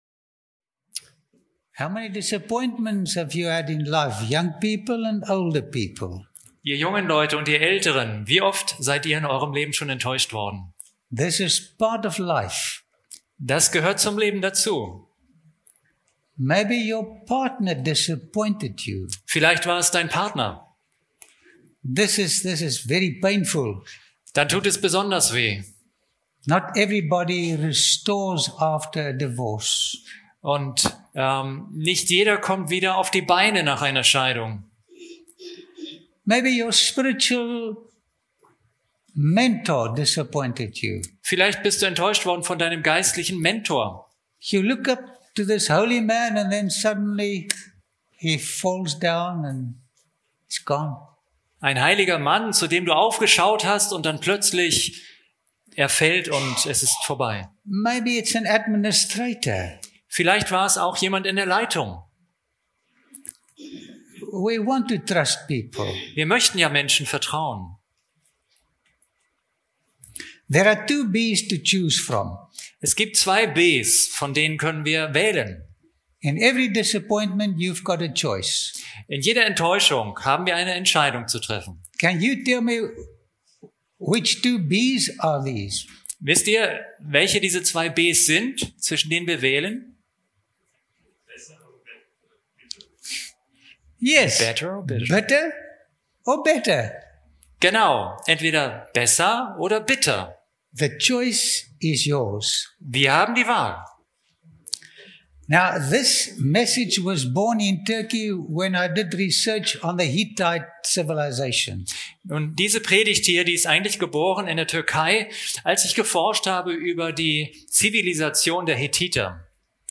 In den reich bebilderten Vorträgen erhält der Zuschauer nicht nur faszinierende Einblicke in vergangene Kulturen, sondern begegnet Menschen, die vor Tausenden von Jahren ganz ähnliche Freuden und Sorgen hatten wie wir – und deren ermutigende Erfahrungen auch heute noch erlebbar sind, wenn wir von ihnen lernen und den verlorenen Schatz des Vertrauens in Gott wiederfinden.